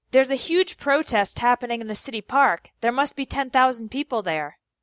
The meaning of the word changes depending on its pronunciation.
pro-test (noun) – A group of people who are against or who disapprove of something
pro-test (verb) – Taking organized action to disapprove of something